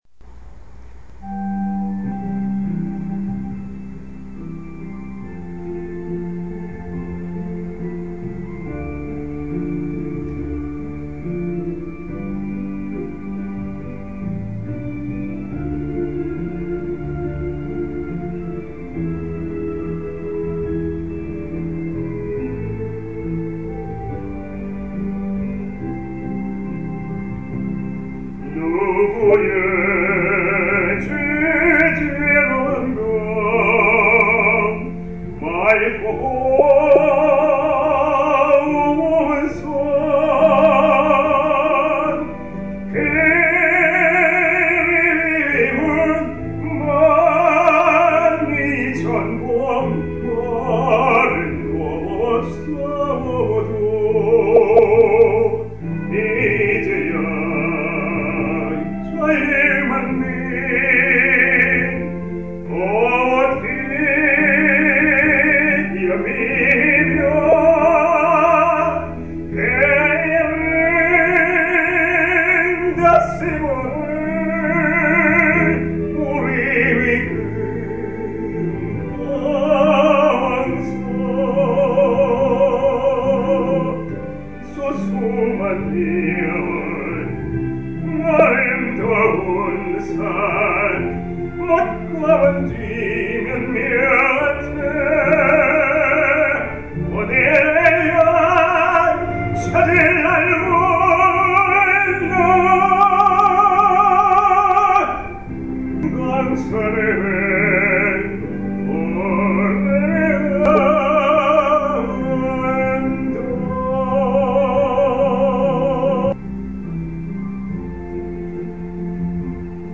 지금 막 불러 봤습니다. 1절보다는 2절의 녹음이 좀 낫습니다.